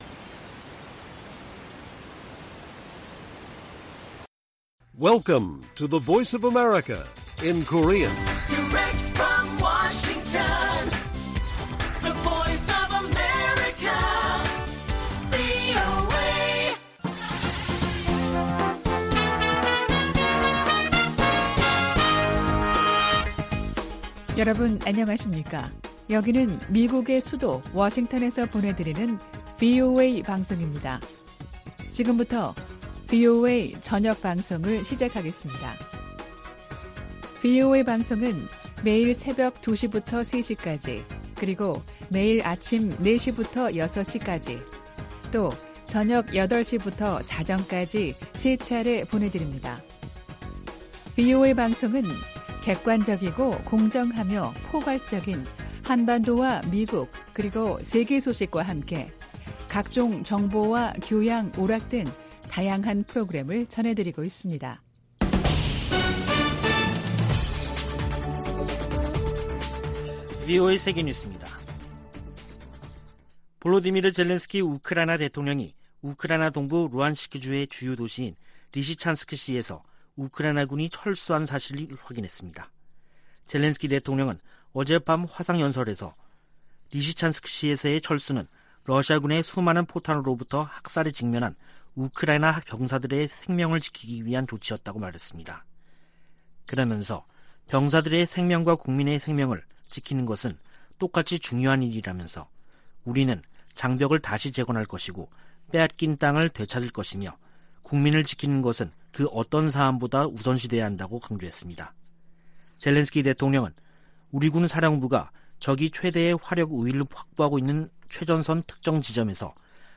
VOA 한국어 간판 뉴스 프로그램 '뉴스 투데이', 2022년 7월 4일 1부 방송입니다. 미국은 북한의 핵·미사일 도발에 대응하고 한반도 비핵화를 위해 동맹과 협력할 것이라고 미군 당국이 밝혔습니다. 위협을 가하는 북한에 일방적으로 대화와 협력을 요청해서는 변화시킬 수 없다고 전 국무부 동아시아태평양 담당 차관보가 진단했습니다. 미국 전문가들은 중국에 대한 한국의 전략적 모호성은 이익보다 대가가 클 것이라고 말했습니다.